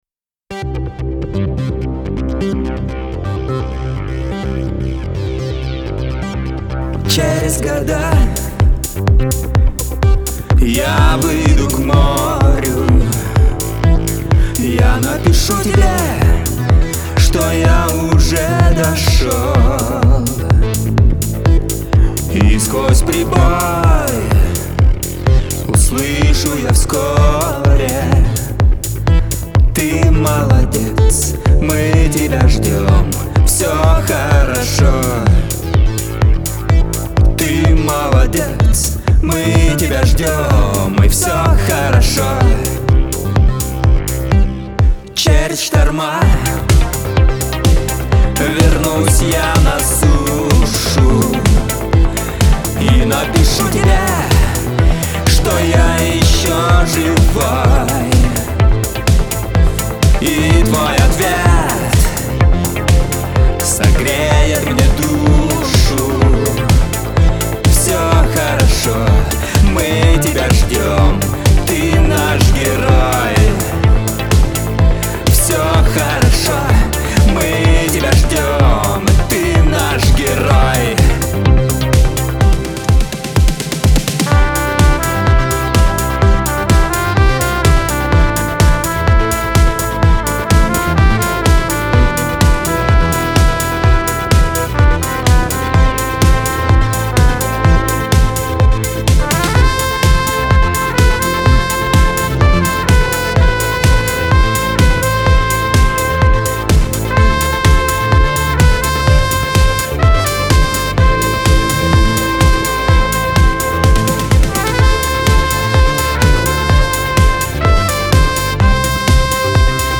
Через Года" (electronic mix
синтезаторы
труба.